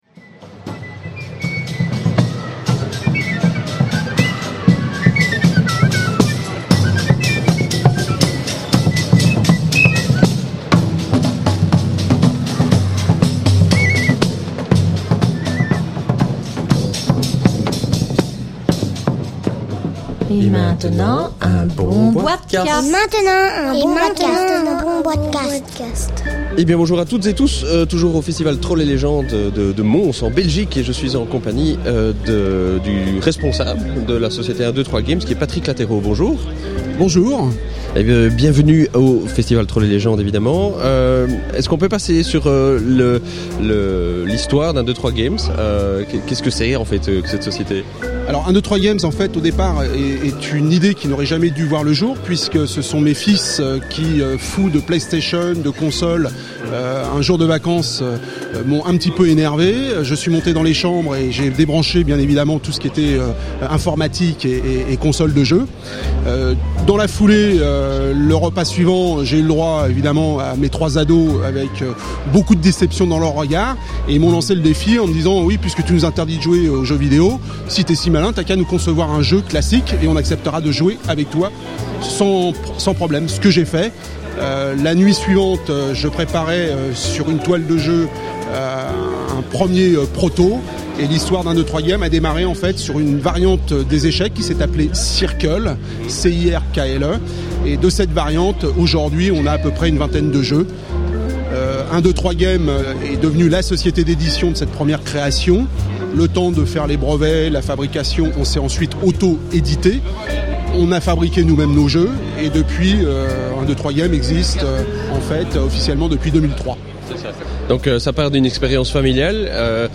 (enregistré lors du Festival Trolls et Légendes 2009 à Mons/Belgique)